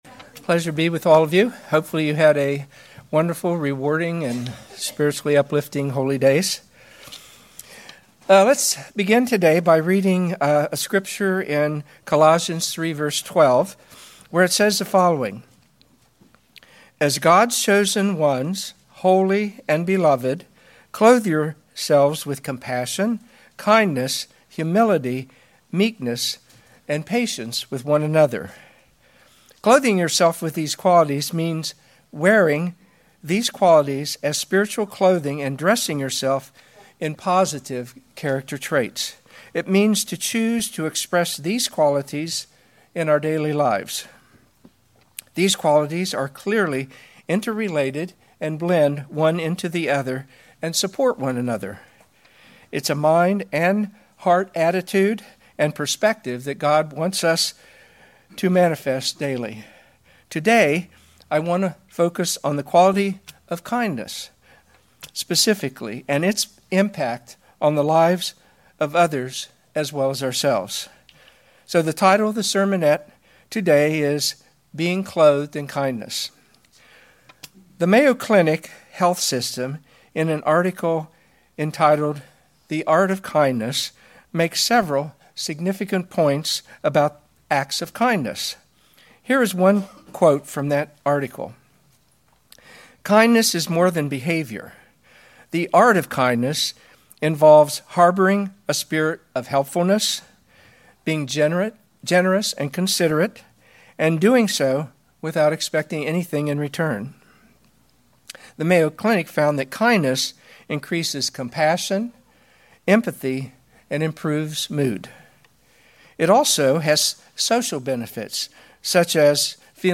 This sermonette identifies some of the qualities and traits of clothing ourselves in kindness. It also identifies some of the behaviors associated with unkindness and how these grieve God and adversely affect the body of Christ, the Church.
Given in Cincinnati East, OH